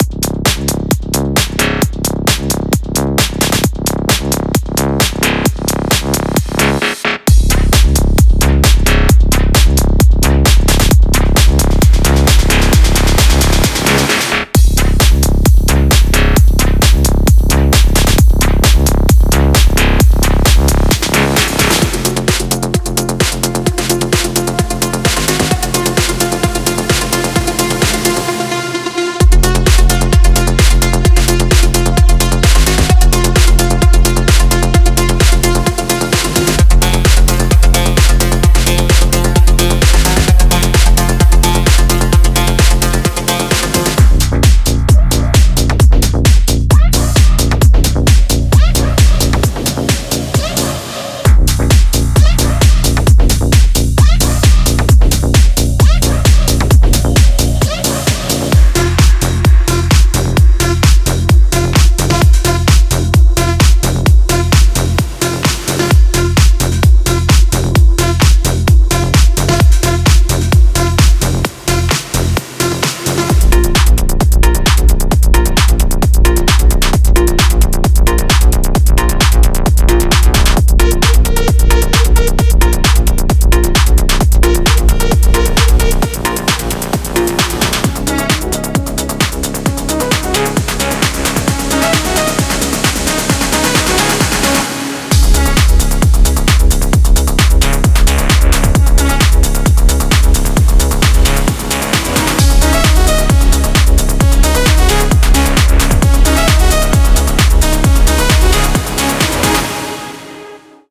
Electro House House Tech House